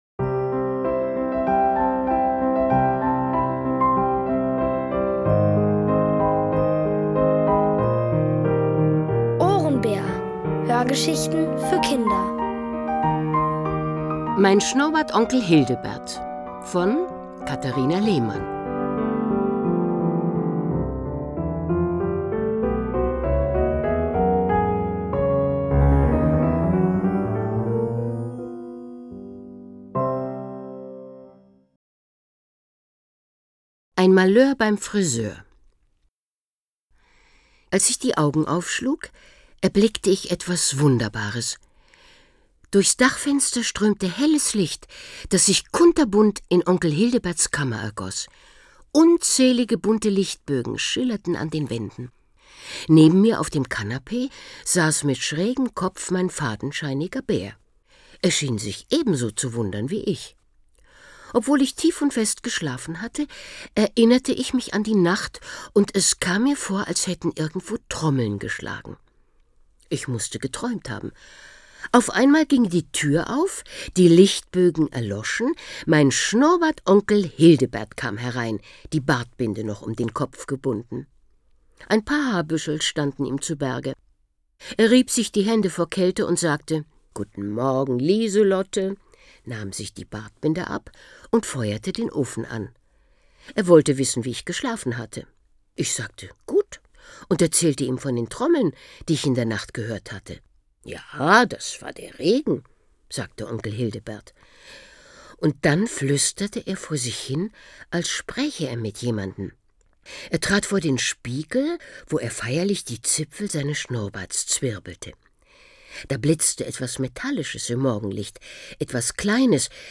Von Autoren extra für die Reihe geschrieben und von bekannten Schauspielern gelesen.
Es liest: Uta Hallant.